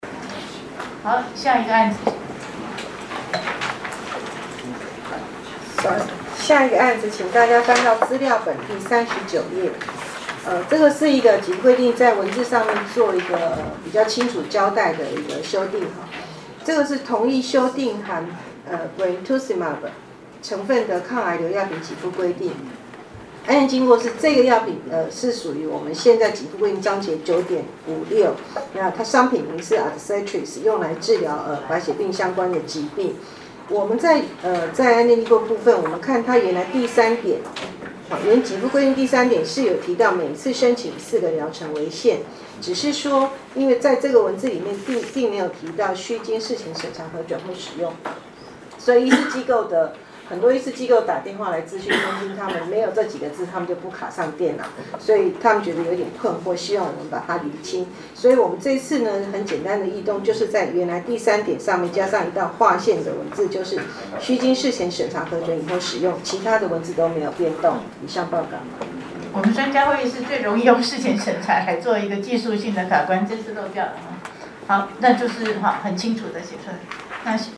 「全民健康保險藥物給付項目及支付標準共同擬訂會議」第24次(106年2月)會議實錄錄音檔